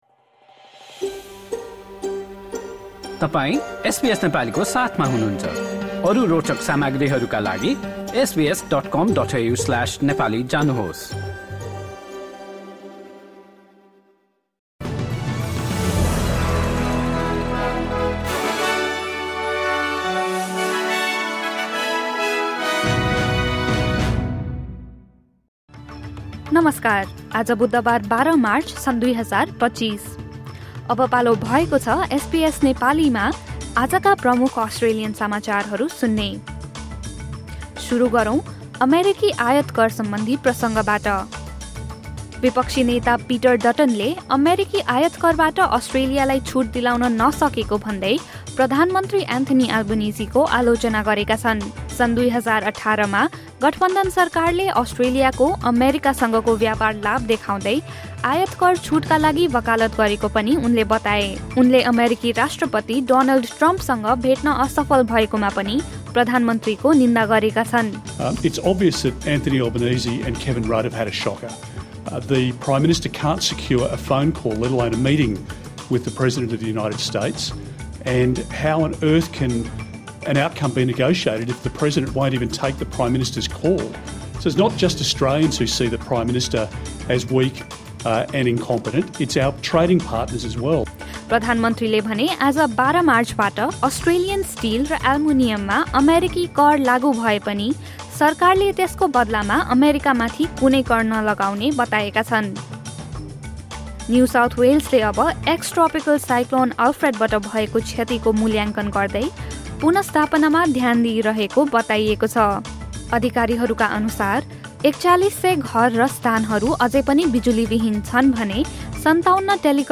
SBS Nepali Australian News Headlines: Wednesday, 12 March 2025